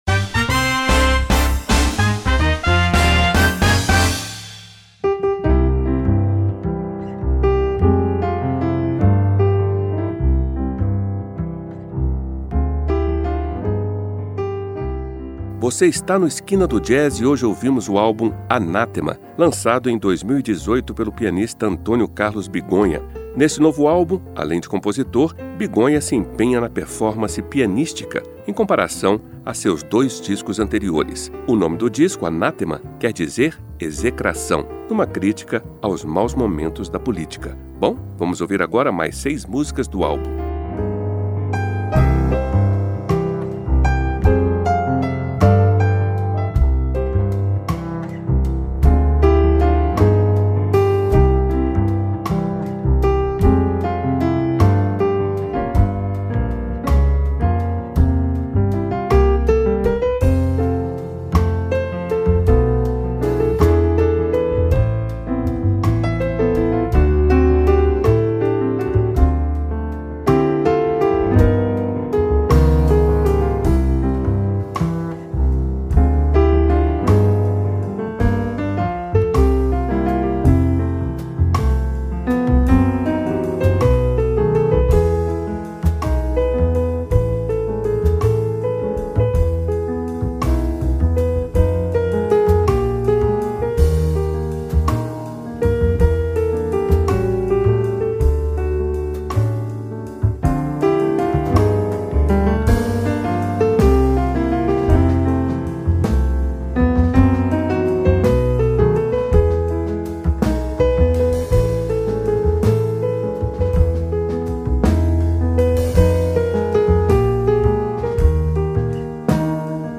no piano